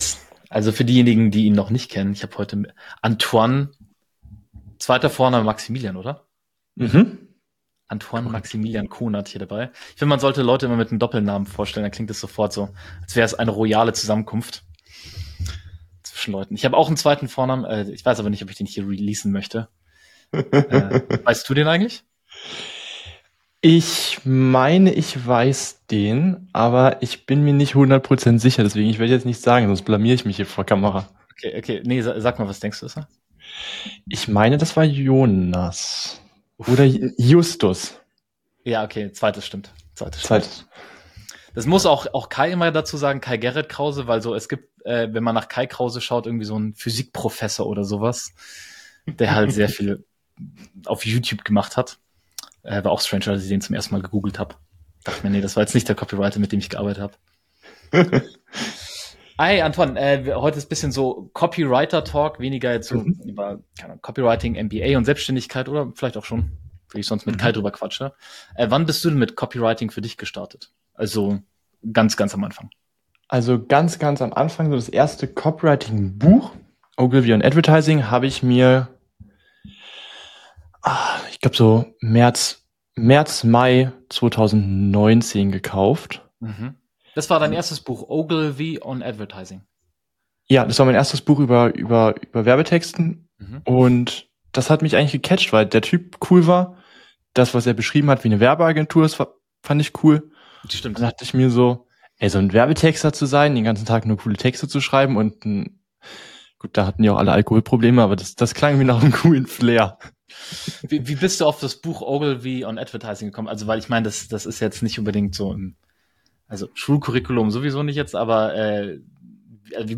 100k Copywriter im Interview